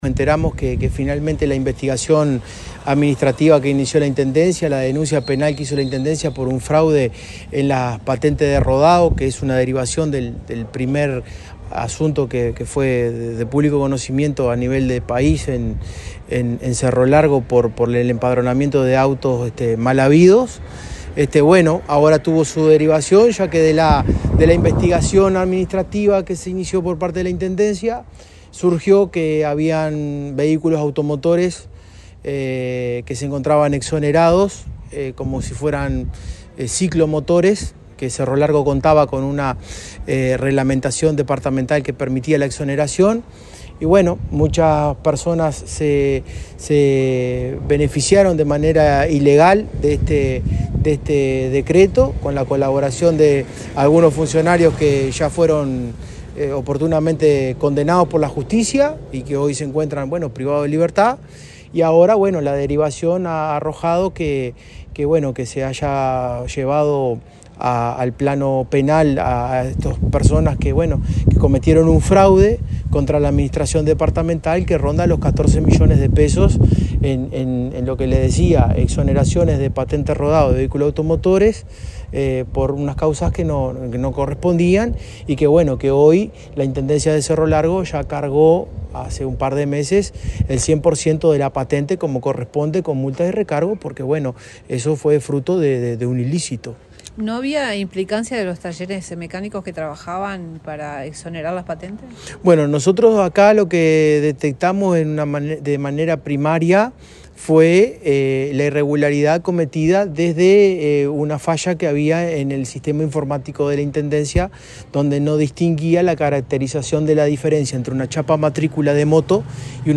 Entrevista a Rafael Rodríguez - director de Hacienda de la Intendencia de Cerro Largo: